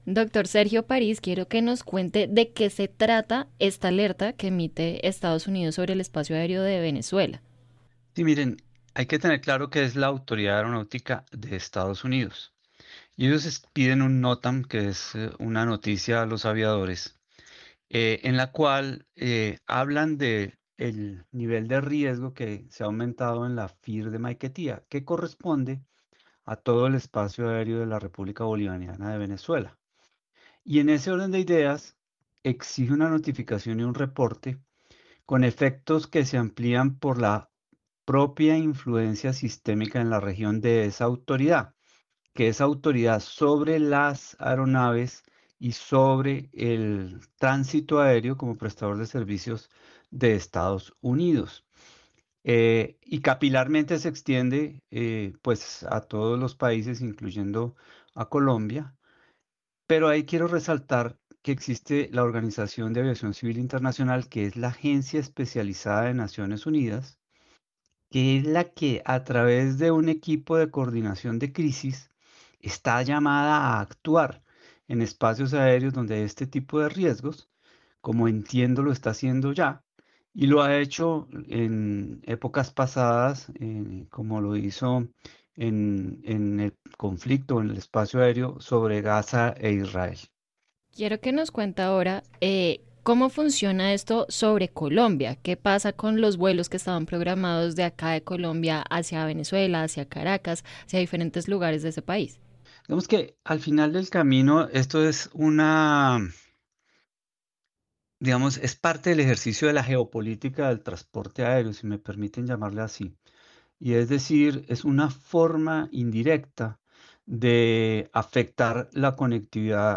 El exdirector de la Aeronáutica civil habló en Caracol Radio sobre los riesgos que implica la cancelación de los vuelos entre Colombia y Venezuela.
Entrevista_Sergio_Paris_69213_cut.mp3